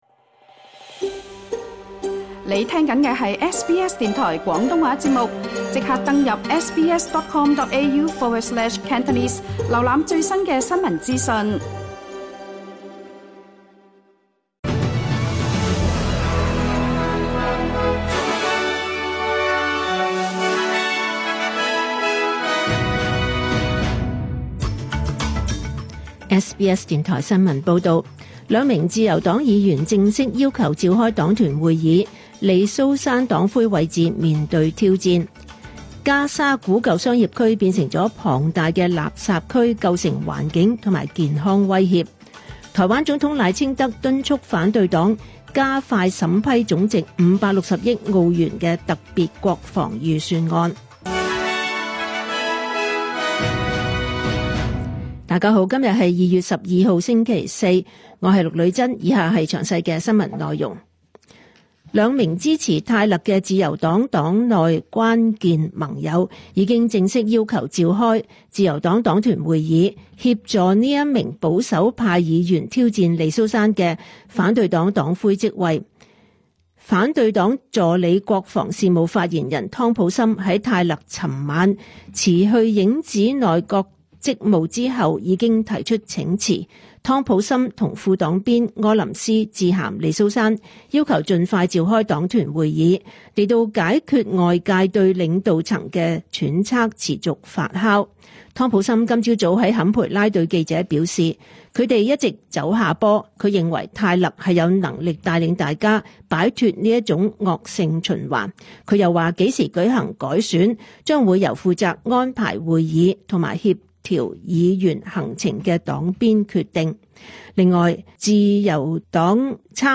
SBS廣東話新聞 (2026 年 2 月 12 日)
2026 年 2 月 12 日 SBS 廣東話節目詳盡早晨新聞報道。